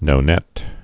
(nō-nĕt)